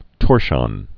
(tôrshŏn)